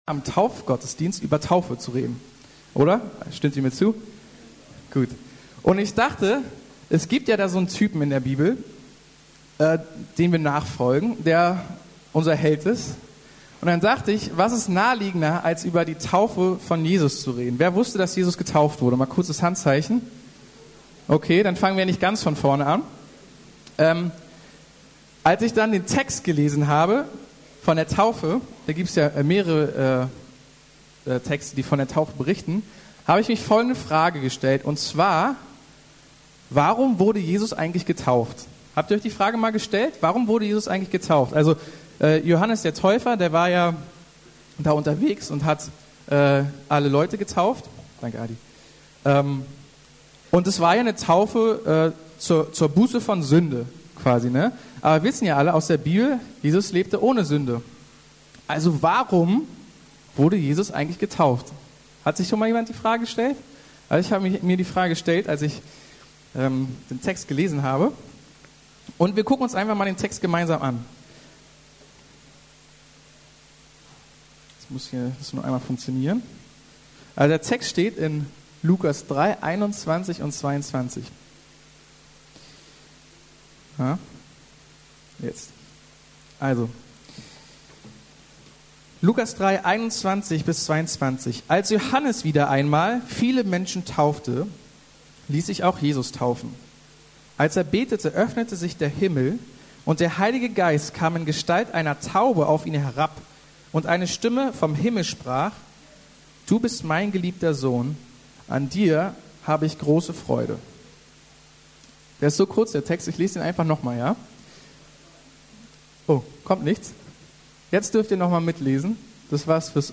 Du bist mein geliebter Sohn... ~ Predigten der LUKAS GEMEINDE Podcast